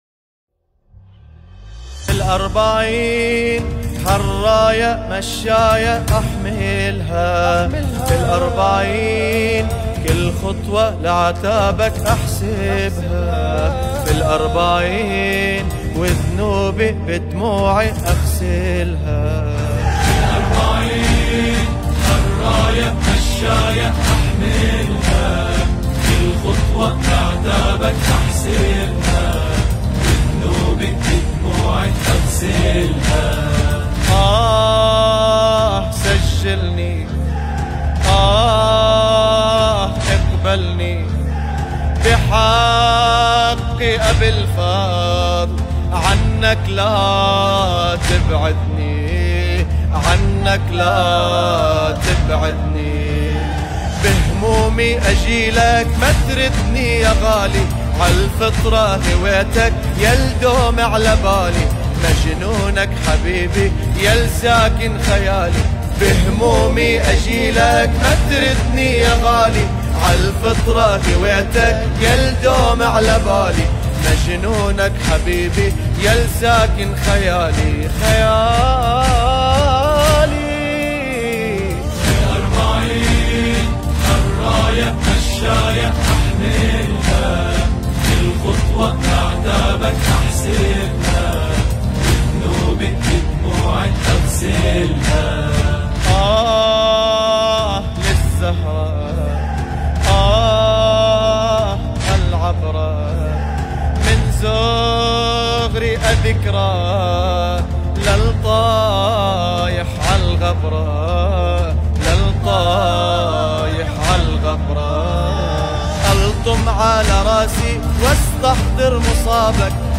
الهندسة الصوتیة والتوضیع